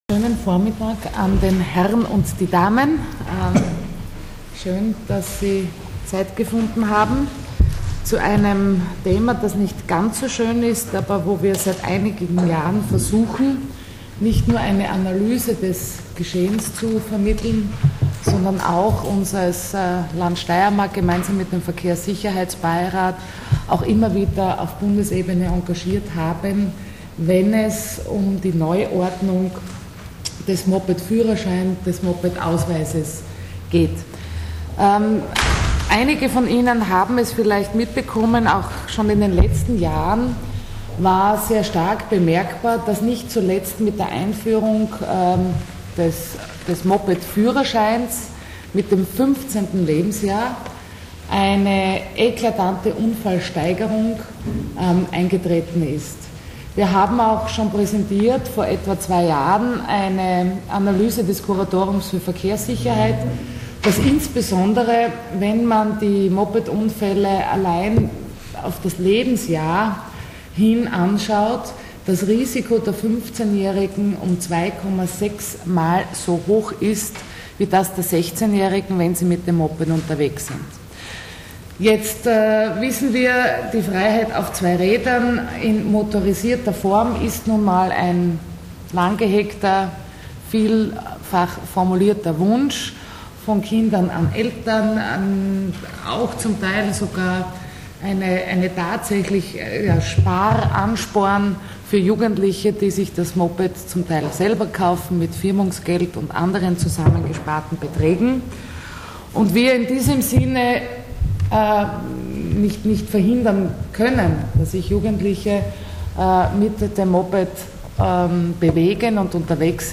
O-Ton: Negativverlauf bei Mopedunfällen
Graz (20.07.2010).- Heute Vormittag präsentierte Verkehrslandesrätin Kristina Edlinger-Ploder gemeinsam mit dem Verein "Große Schützen Kleine" im Medienzentrum Steiermark die Mopedstudie "Sicher auf zwei Rädern".